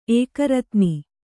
♪ ēkaratni